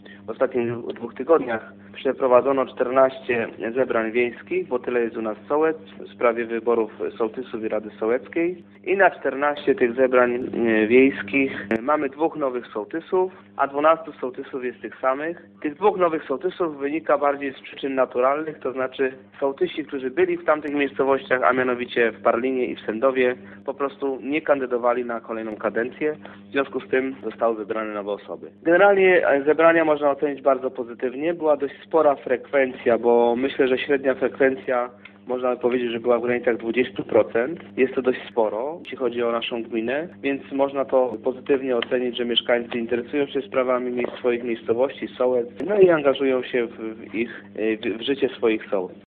W gminie Dąbrowa zakończyły się spotkania wiejskie, podczas których wybrani zostali sołtysi oraz rady sołeckie. W wielu przypadkach społeczność wiejska zaufała swoim dotychczasowym sołtysom i wybrała ich na kolejną kadencję 2015-2018. Podobnie było na terenie gminy Dąbrowa, mówi wójt Marcin Barczykowski.